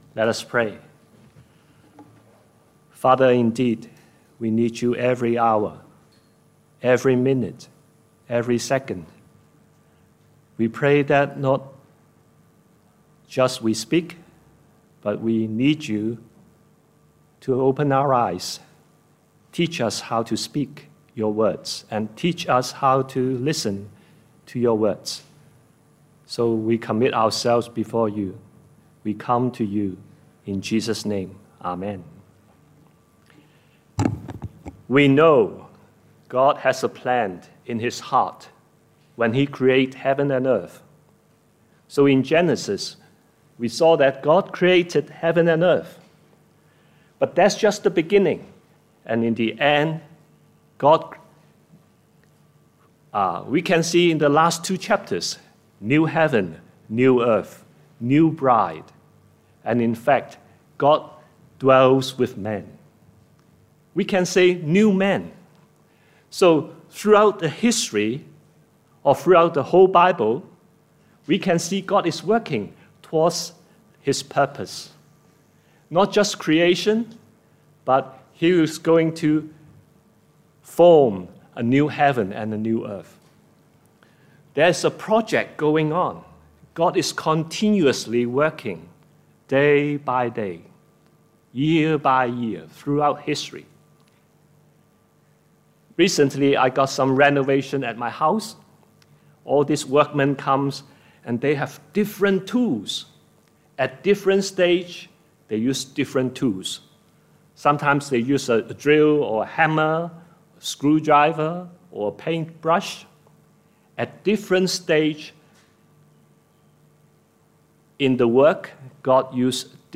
Scripture reference: 1 Kings 19 A short talk about the challenges the prophet faced when fulfilling his ministry.